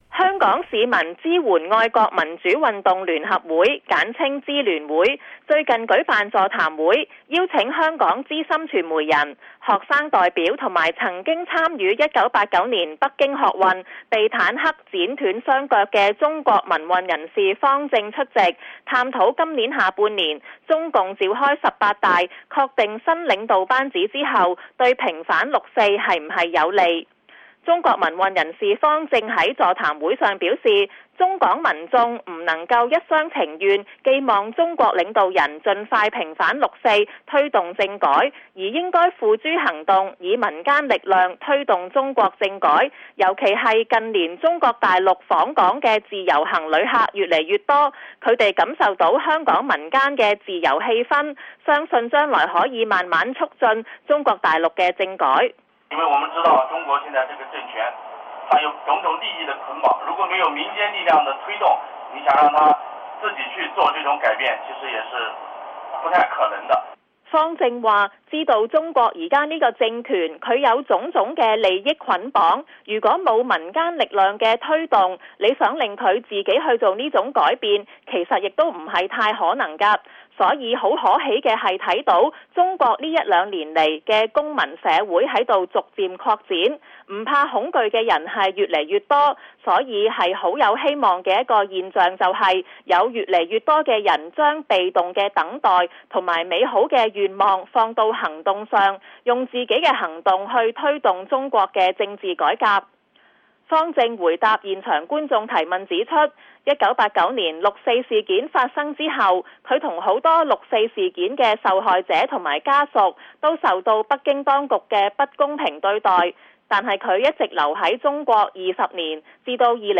有香港政治團體最近舉行座談會，探討近期中國政局的變化是否有利平反六四。